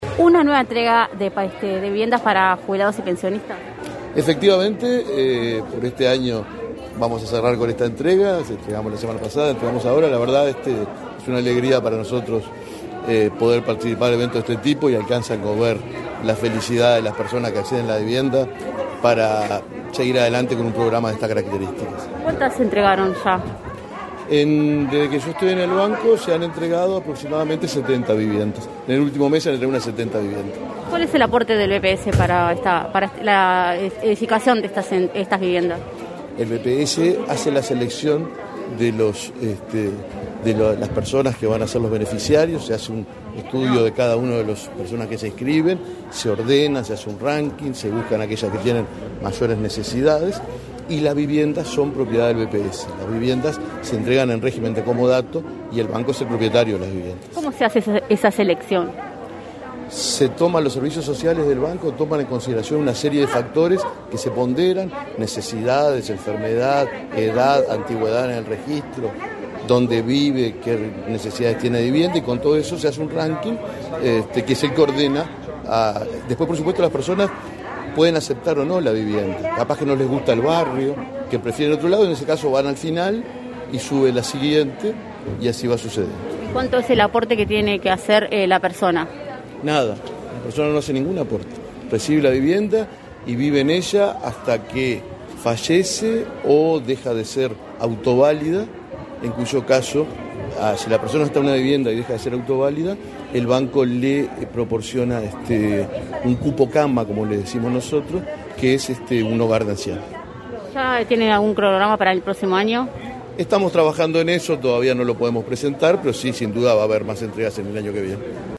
Declaraciones del presidente del Banco de Previsión Social, Alfredo Cabrera Casas
Tras el acto, Cabrera efectuó declaraciones a Comunicación Presidencial.